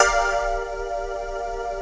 35SYNT01  -R.wav